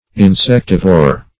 Insectivore \In*sec"ti*vore\, n.; pl. Insectivores (-v[=o]rz).
insectivore.mp3